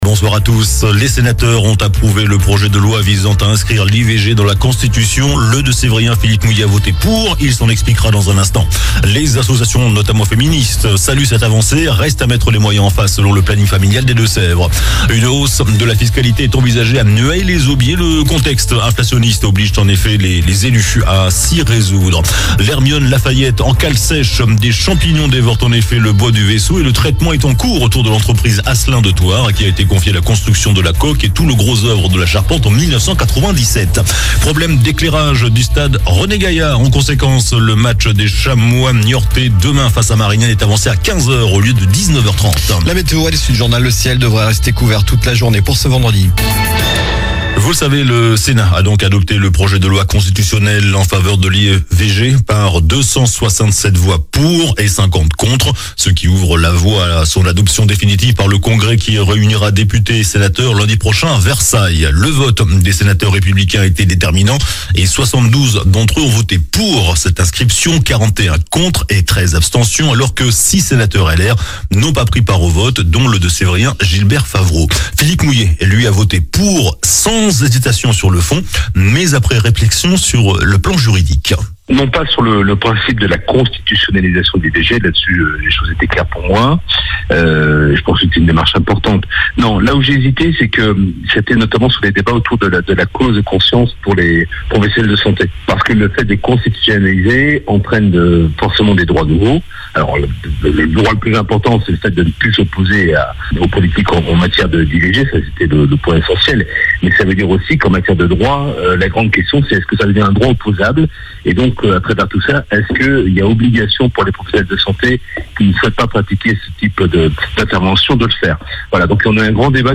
JOURNAL DU JEUDI 29 FEVRIER ( SOIR )